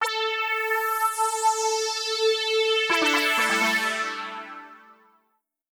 SYNTHPAD031_DISCO_125_A_SC3.wav